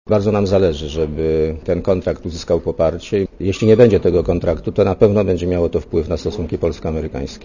Mówi premier leszek Miller (29 KB)